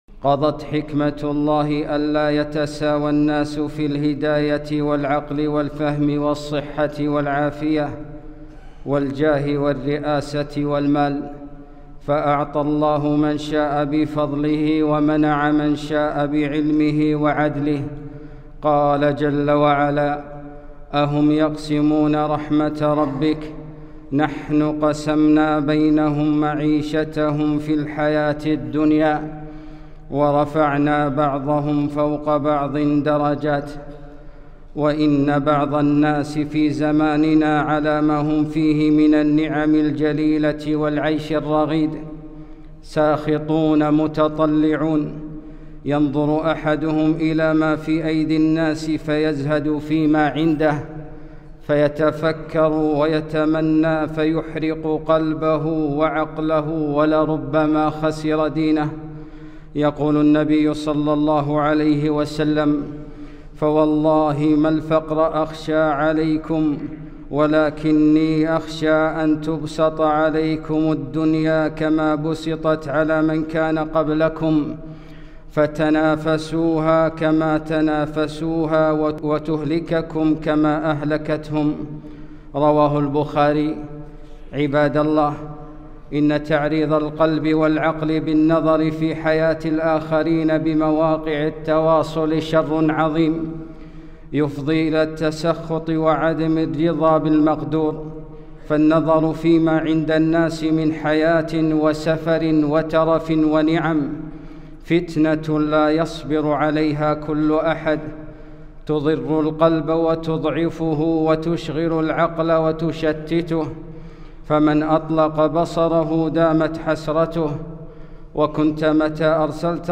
خطبة - شؤمُ المقارنات